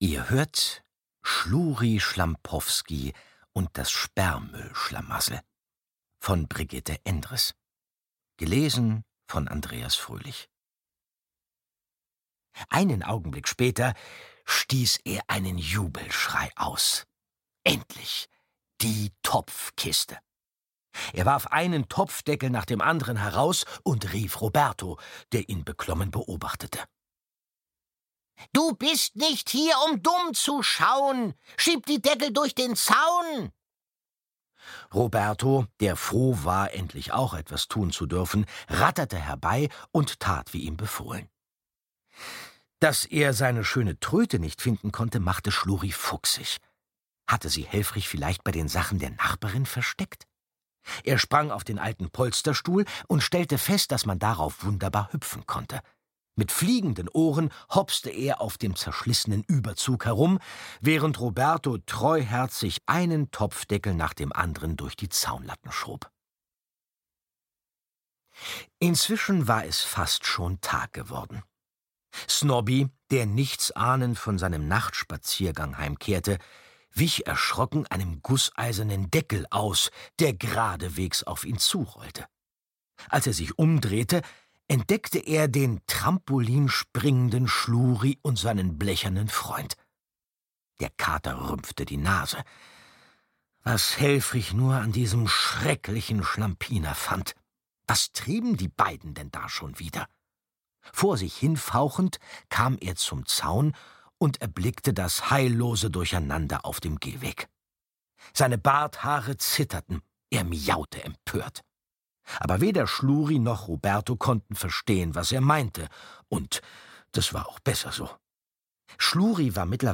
Andreas Fröhlich (Sprecher)
Warmherzig, witzig und frech gibt er Schluri und der Spielzeugbande unvergessliche Stimmen.
Schlagworte Bär • Hörbuch; Lesung für Kinder/Jugendliche • Krach • Müllpresse • Opa Helfrich • Puppendoktor • Roboter • Zwerg; Kinder-/Jugendlit.